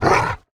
dog_01.wav